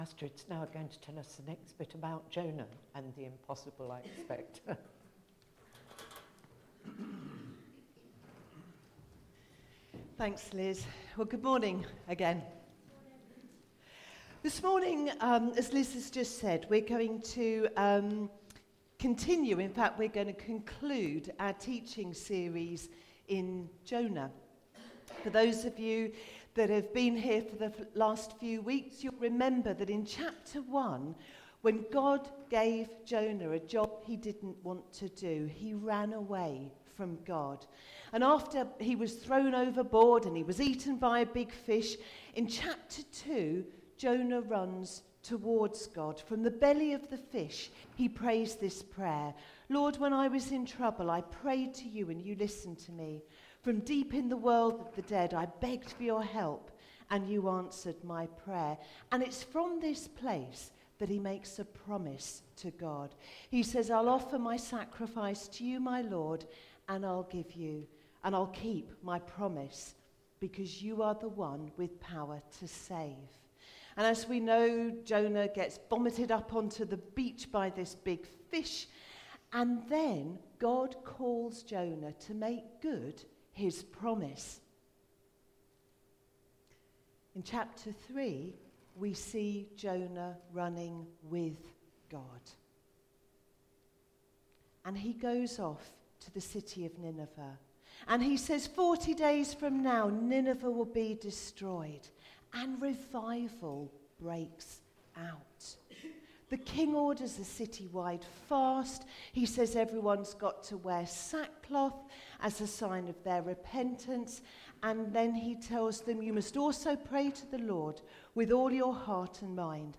A message from the series "Elijah: Faith and Fire."